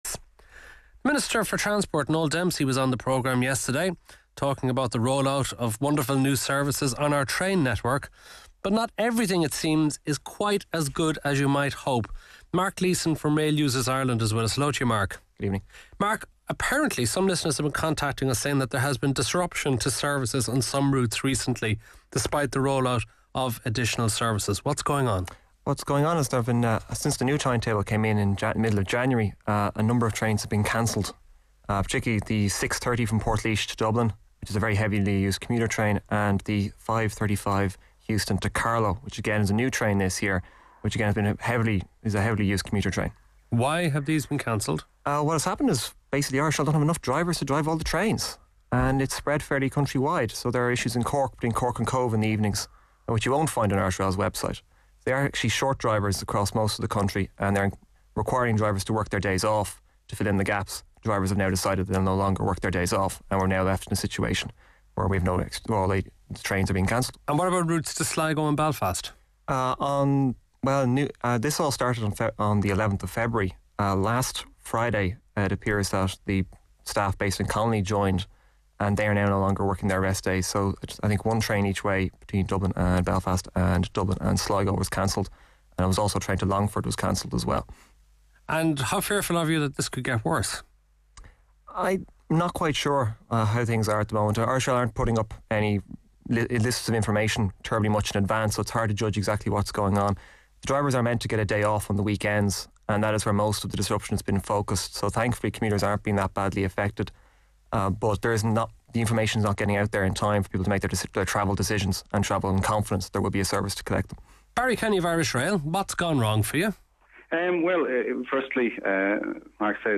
P.S. the last question gets cut off in the end.